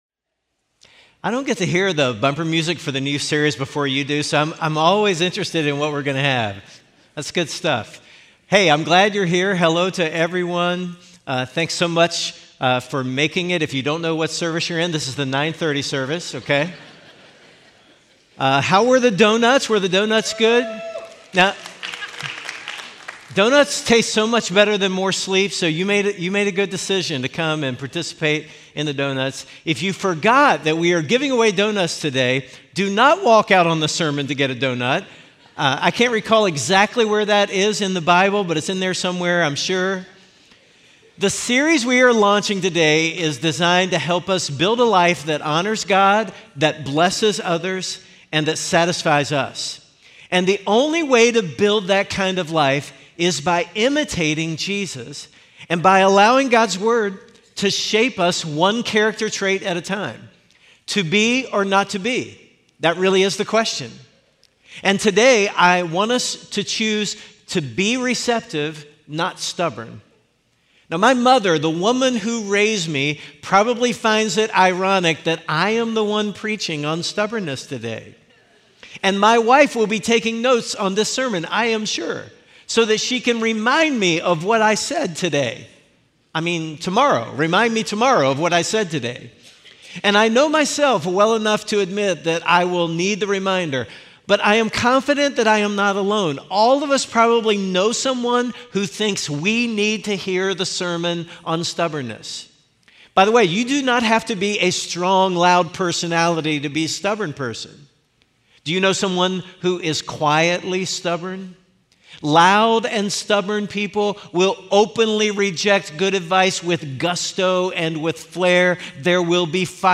Week four of our sermon series on the book of Ecclesiastes!